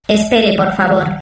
voz nș 0137